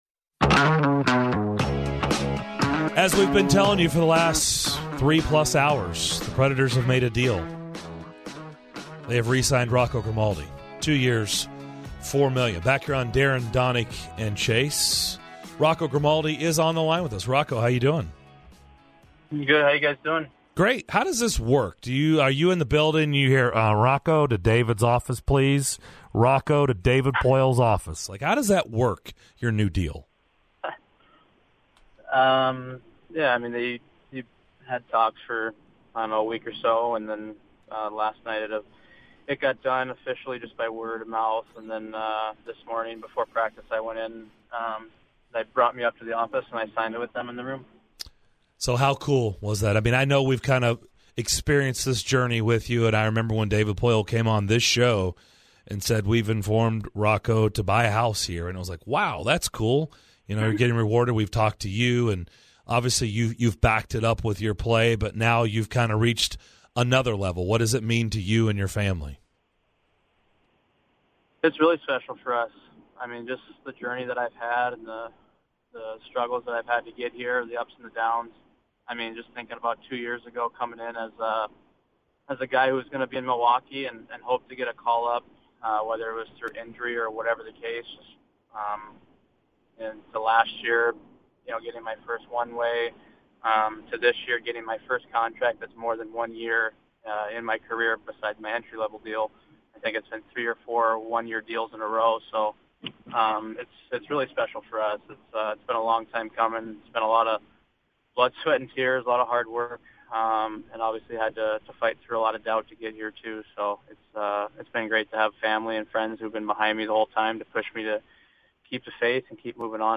Preds forward Rocco Grimaldi joins DDC to discuss his new contract extension!